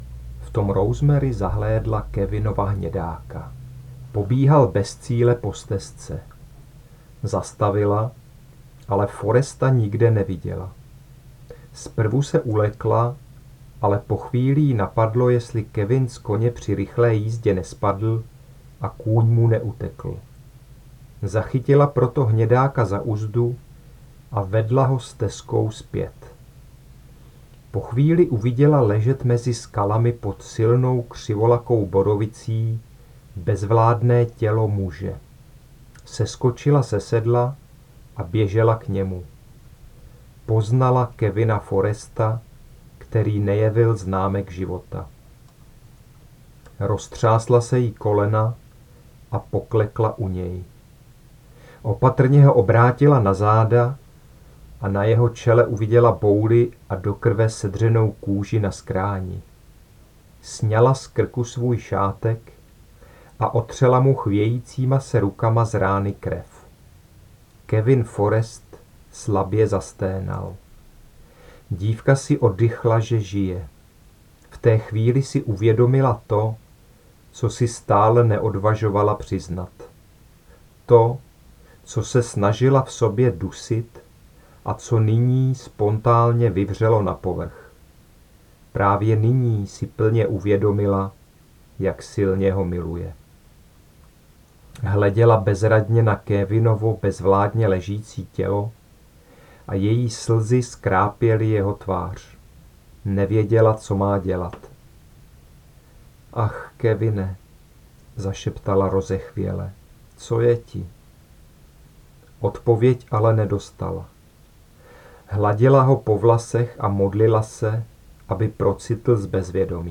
• audiokniha v mp3 cena: 120,- Kč (4 €) (ukázka níže)